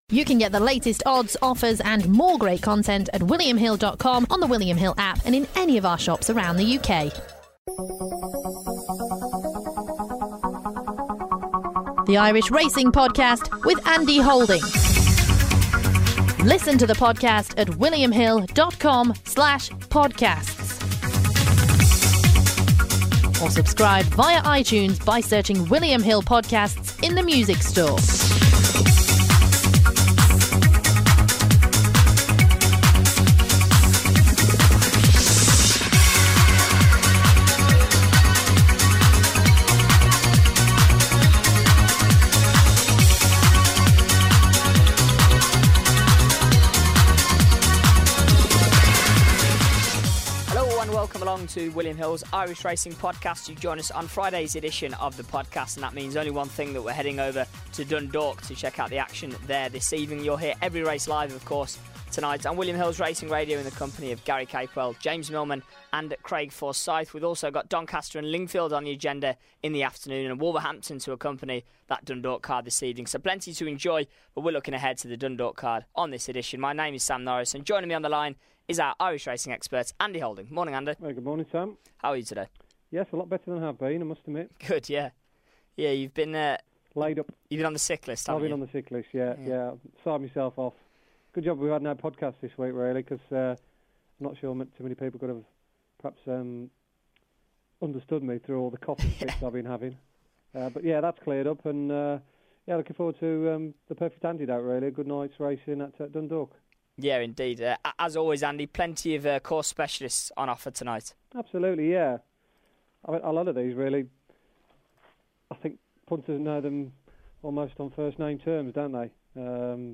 on the line to preview each race in detail before offering his selections.